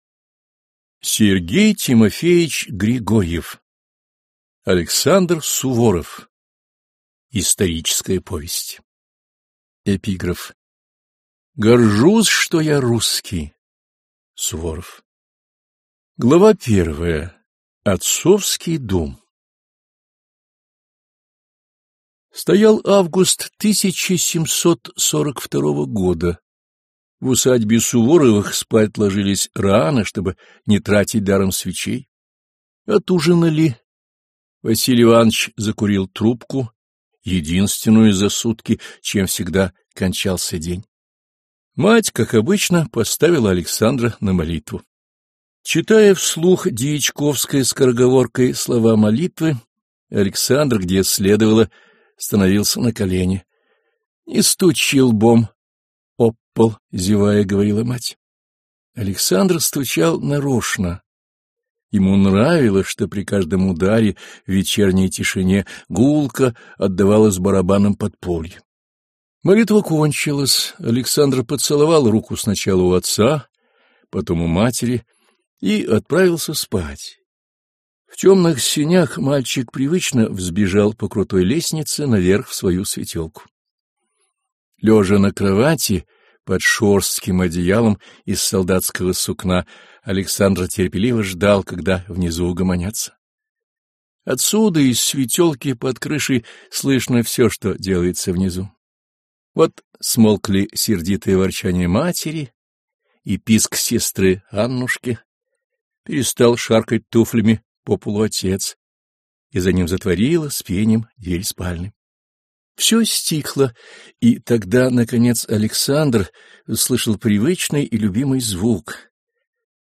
Аудиокнига Александр Суворов | Библиотека аудиокниг